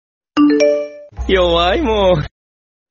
Nada notifikasi “Yowaimo” oleh Satoru Gojo
Kategori: Nada dering